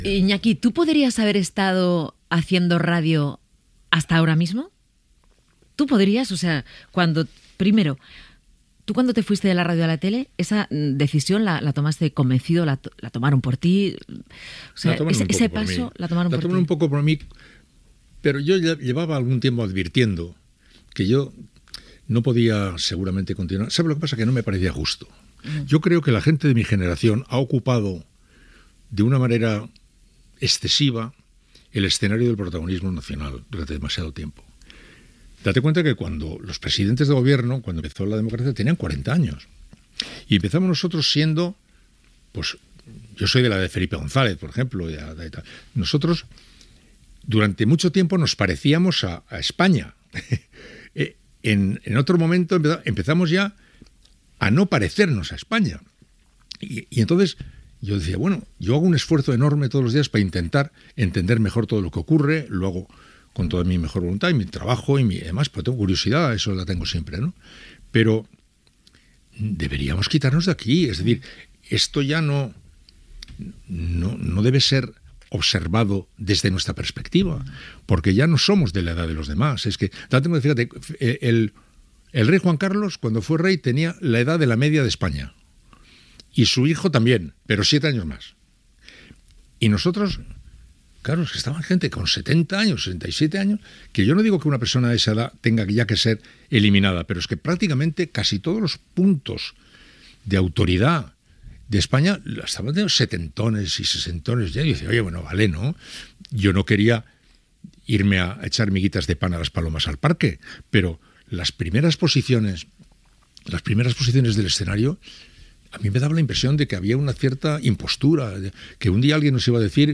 Secció "Gatopardo" amb fragments d'una entrevista al periodista Iñaki Gabilondo.
Entreteniment
FM